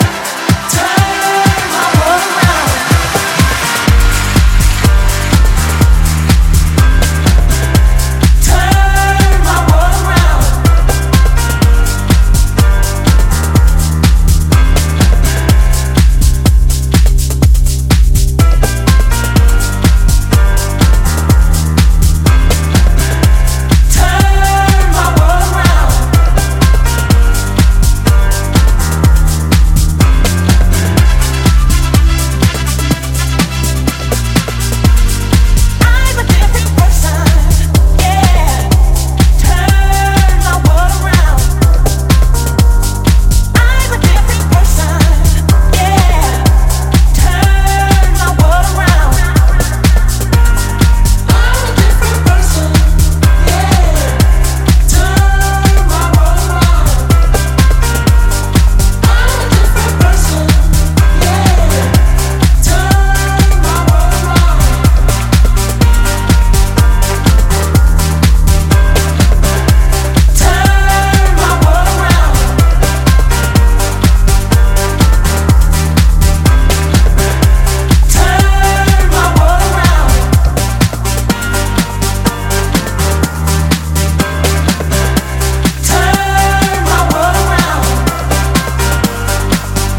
modern day house classic